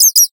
sounds / mob / bat / idle1.ogg